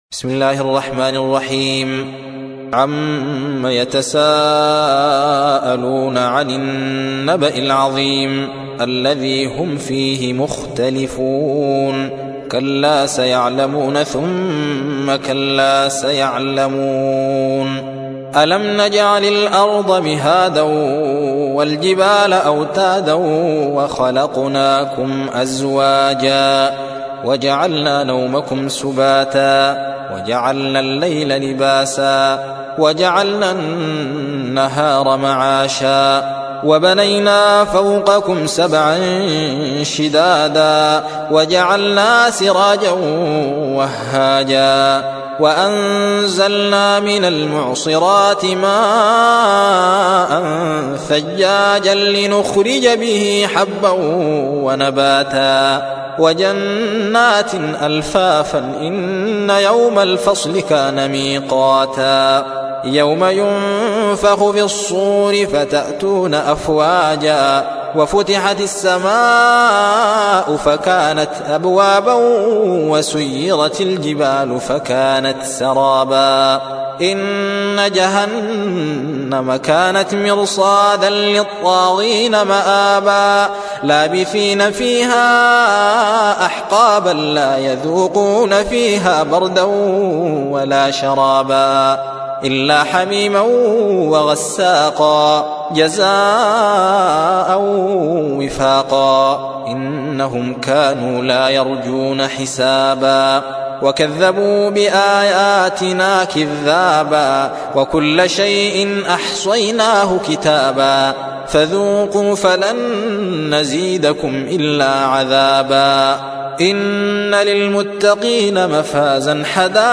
78. سورة النبأ / القارئ